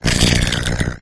rolycoly_ambient.ogg